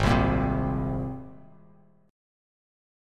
G#add9 chord